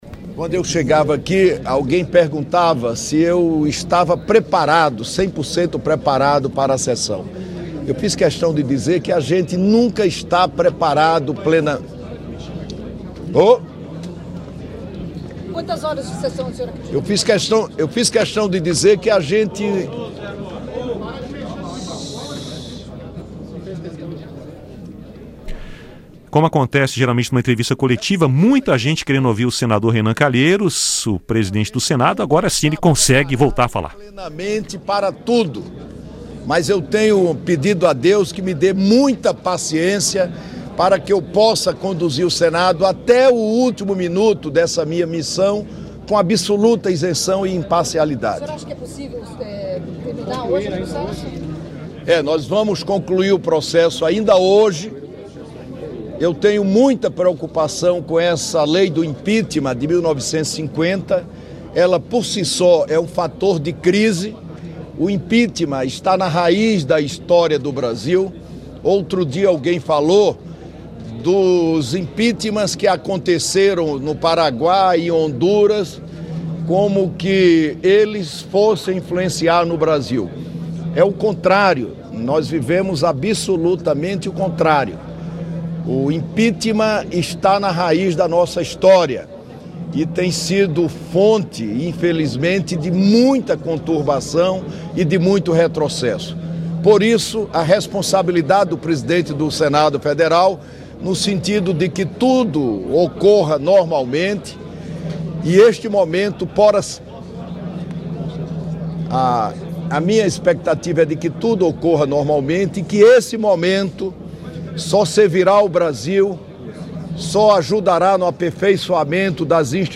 Presidente Renan Calheiros fala em entrevista sobre sua expectativa para sessão
Coletiva